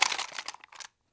grenade_reloadgarand4.wav